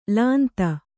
♪ lānta